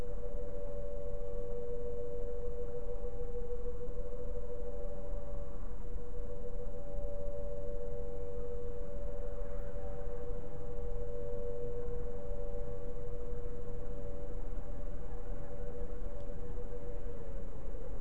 32GE 6/8/23 Site alarms 10/8/23 00:14 AM